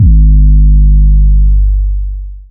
DDW2 808 2.wav